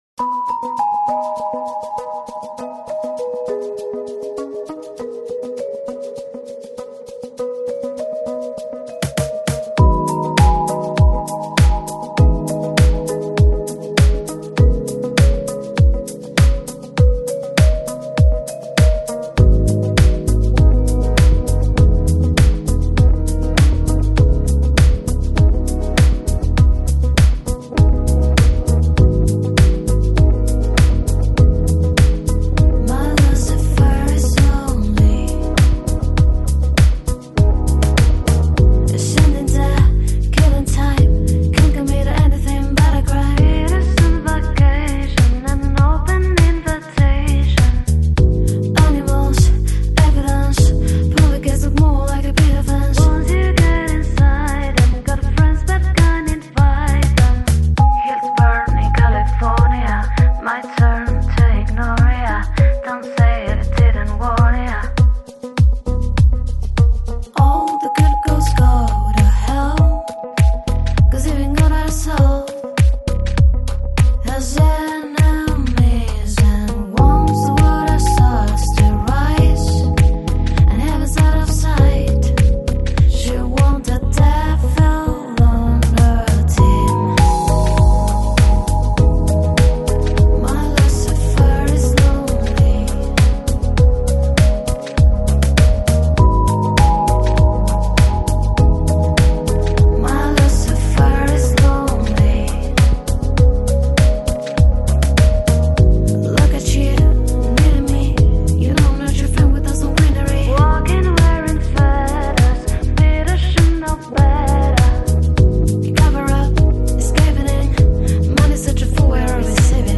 Жанр: Lounge, Pop, Jazz